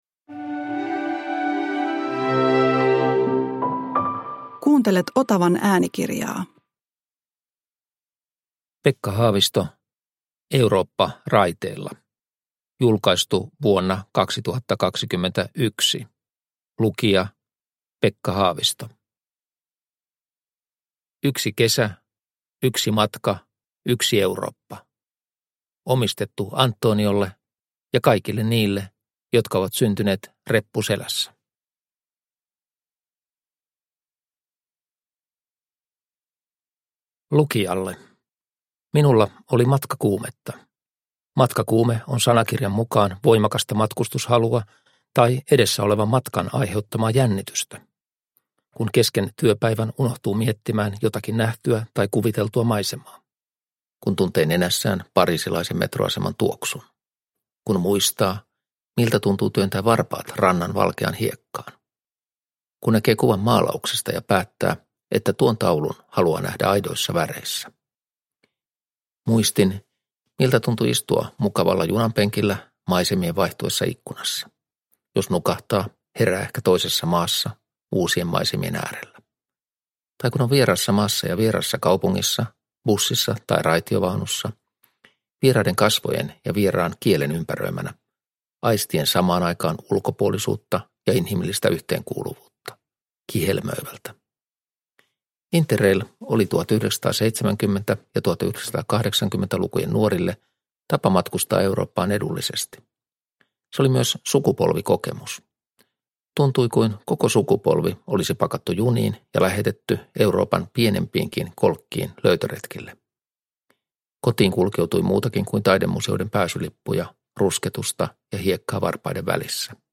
Eurooppa raiteilla – Ljudbok
Uppläsare: Pekka Haavisto